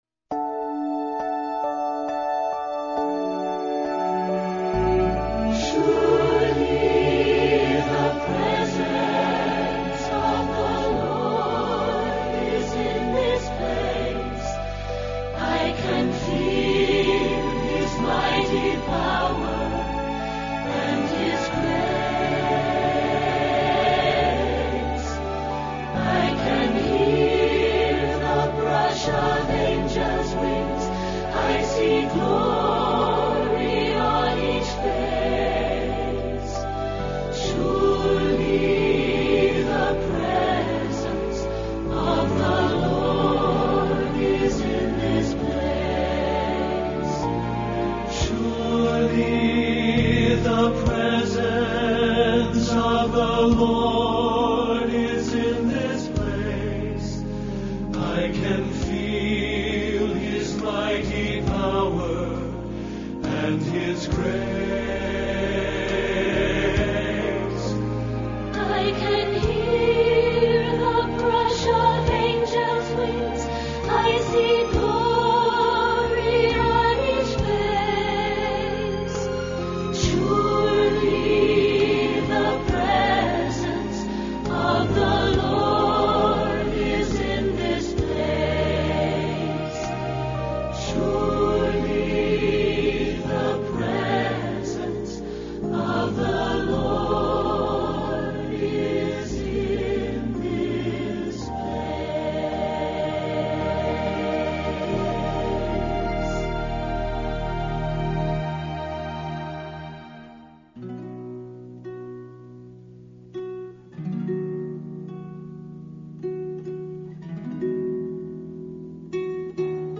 This week’s Devotional Topic This week we will be singing and sharing how we can trust in Jesus Christ as Our Lord.
In addition to the many musical selections of Praise and Worship, we will be sharing a few of the wonderful songs from Don Moen’s musical, “God For Us.” Included in that medley will be the complete reading called, “You Can Trust Him!”